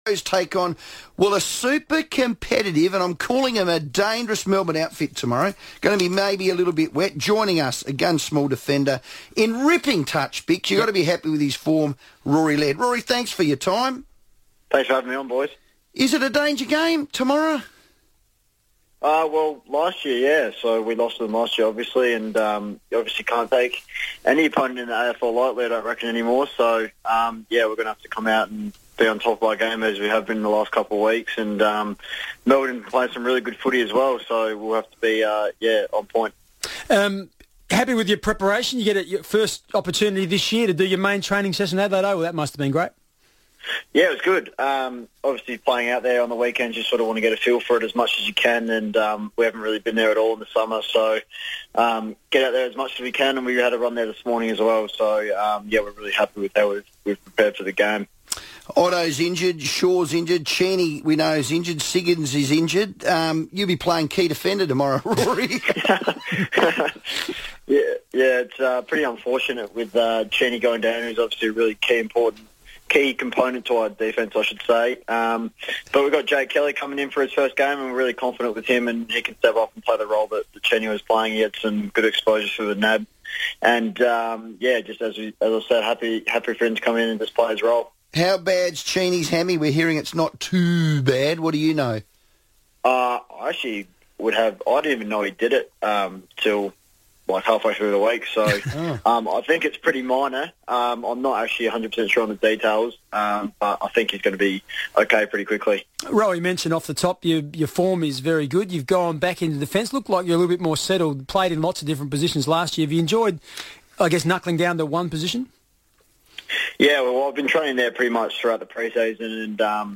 Crows defender Rory Laird joined the FIVEaa Sports Show